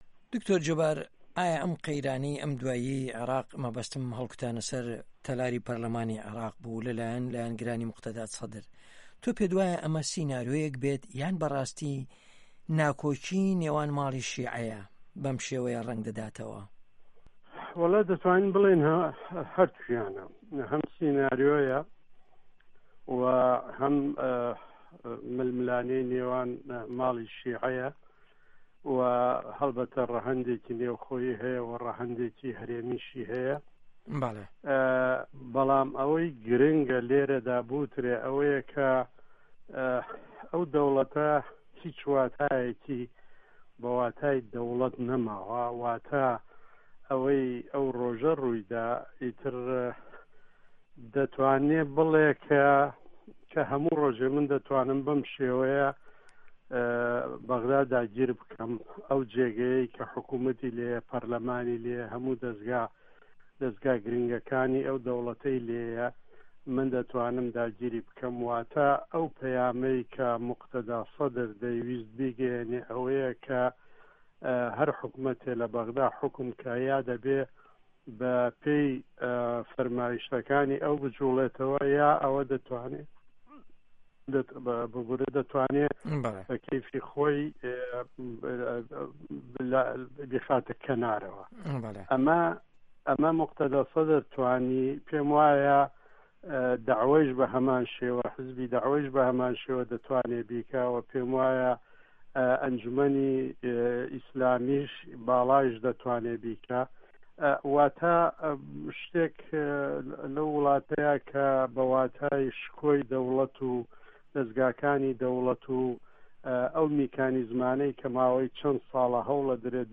عێراق - گفتوگۆکان